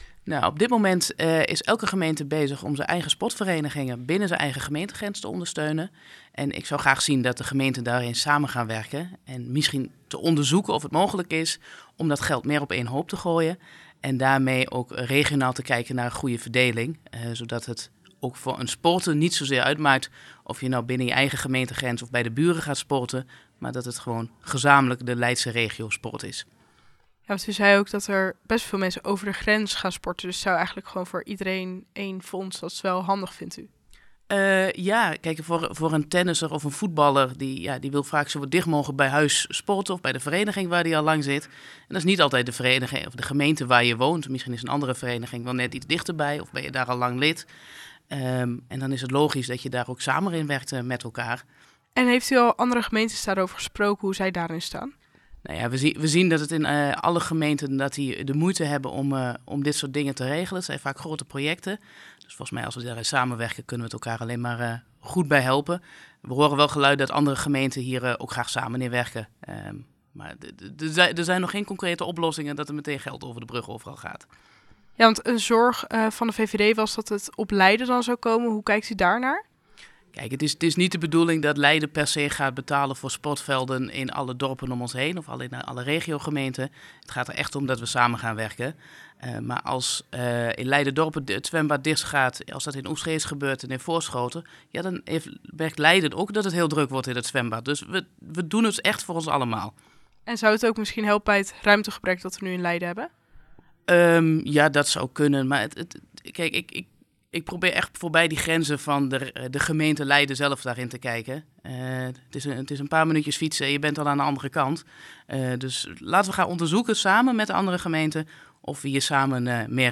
gaat in gesprek met raadslid Linda Beimer over het voorstel.